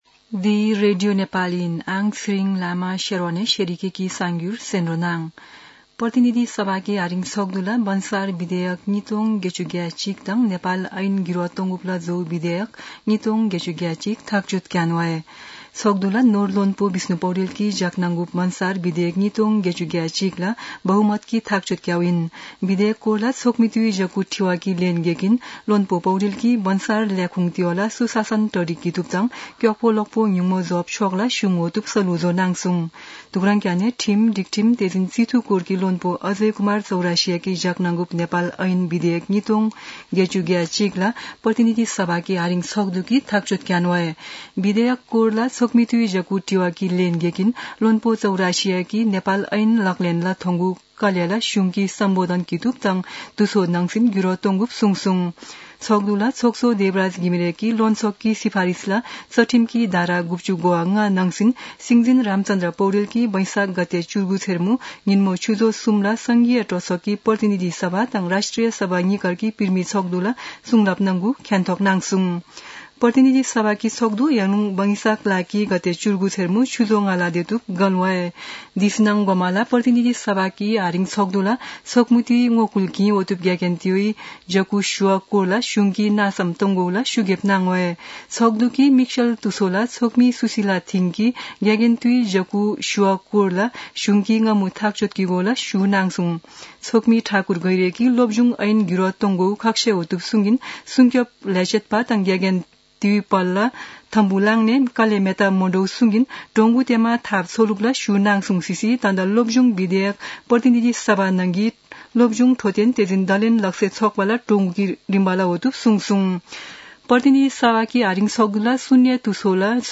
An online outlet of Nepal's national radio broadcaster
शेर्पा भाषाको समाचार : १६ वैशाख , २०८२
shearpa-news-1-4.mp3